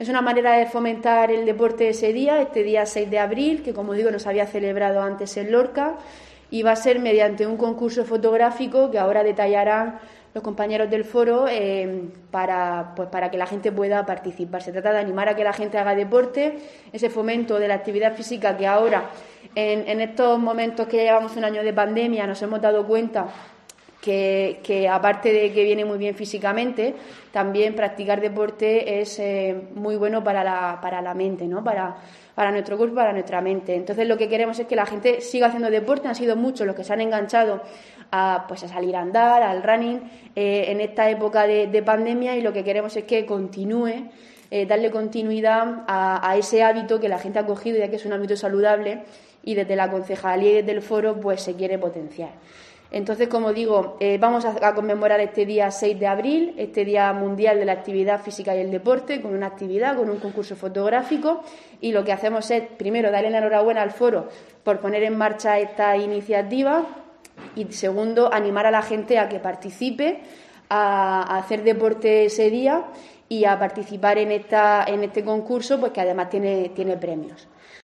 Irene Jódar, edil de deportes del Ayuntamiento de Lorca